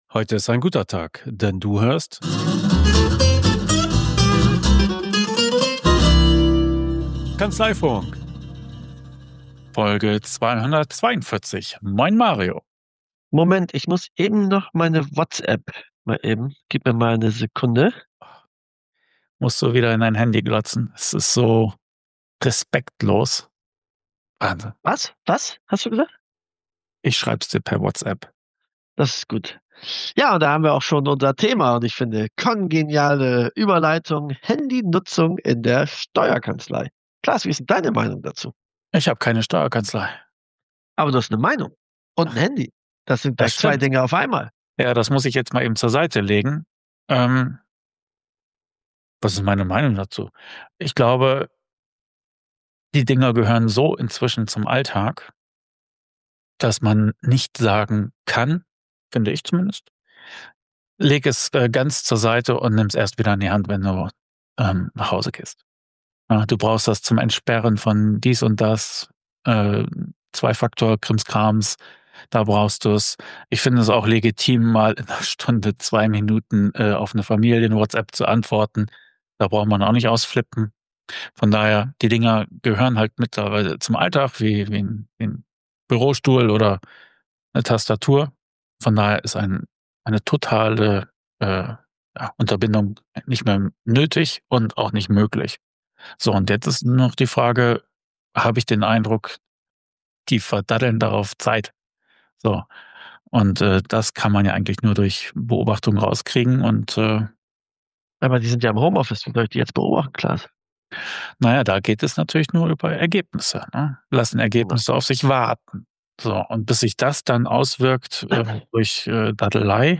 Zwei kaputte Internetsüchtige berichten von ihren Mühen, wieder clean zu werden und wie es am Arbeitsplatz laufen könnte.